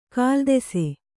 ♪ kāldese